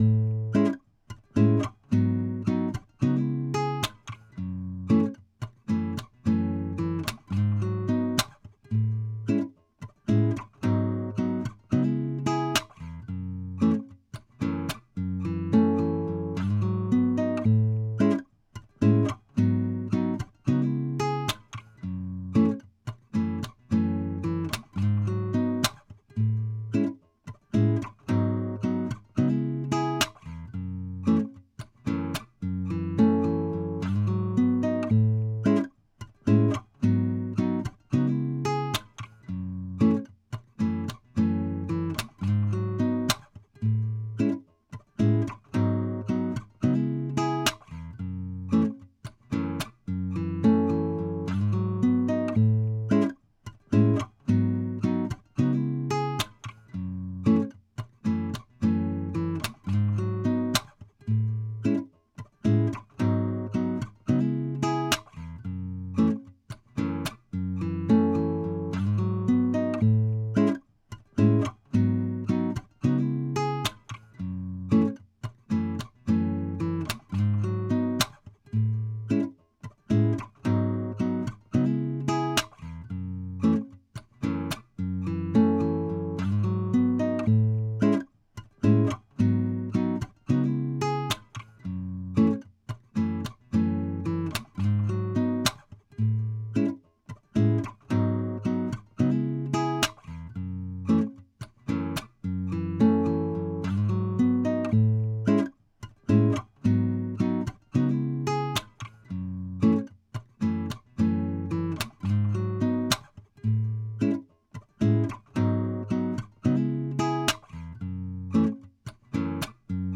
Acoustic, R&B
C# Minor